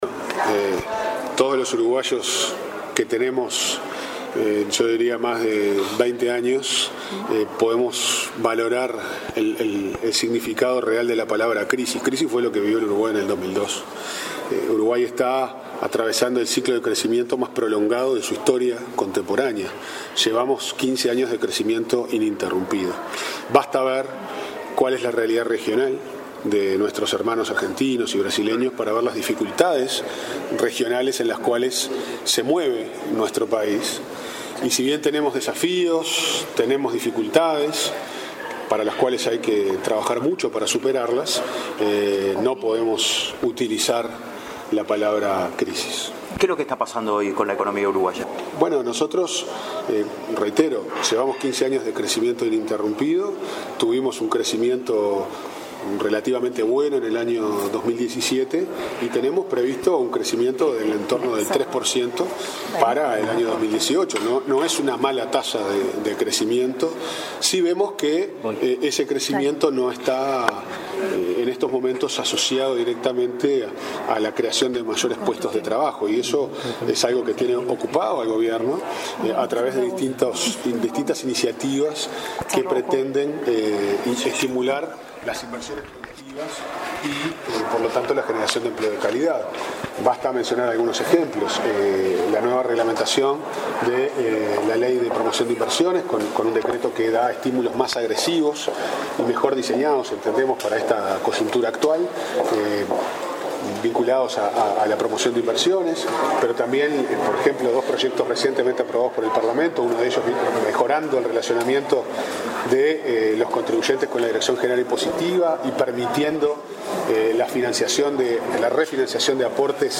El subsecretario de Economía, Pablo Ferreri, dijo a la prensa, este martes en el Parlamento, que el crecimiento del 3 % de la economía uruguaya previsto para este año es parte del ciclo ininterrumpido iniciado hace 13 años, a diferencia de la situación que atraviesan los países de la región. Tras concurrir a la Comisión de Presupuesto por la Rendición de Cuentas, insistió en que crisis fue lo que Uruguay vivió en 2002.